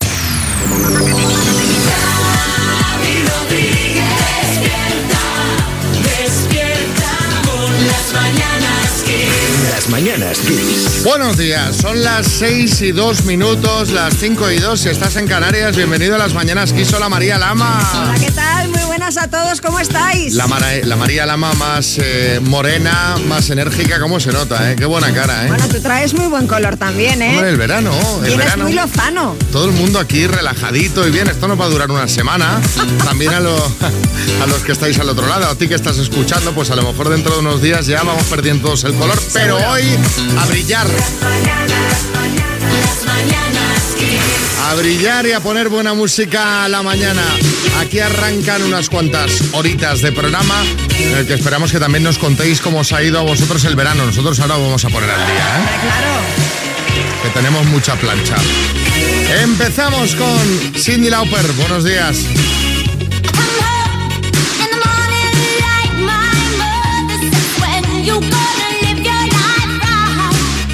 Careta, hora i primers minuts de l'inici de la temporada 2025-2026. Diàleg dels presentadors i tema musical
Entreteniment